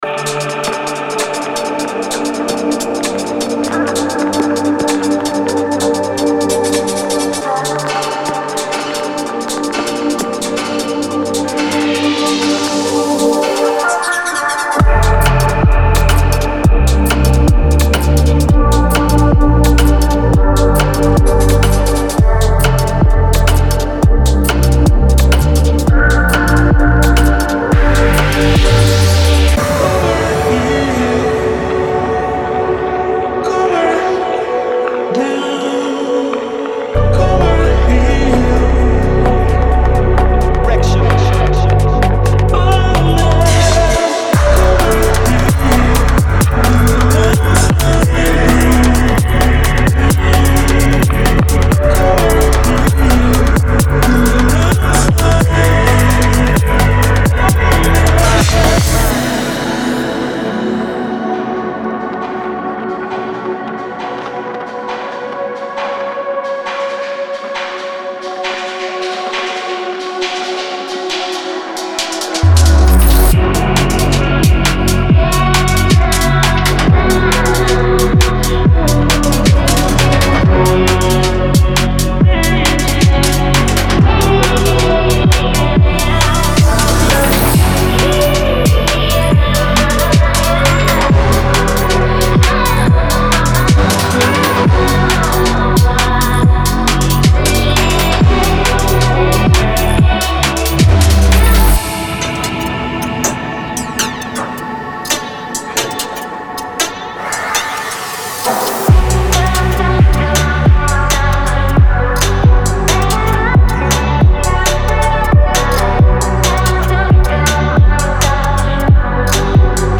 Genre:Garage
デモサウンドはコチラ↓
128, 130, 132, 133, 135, 136, 138, 140 BPM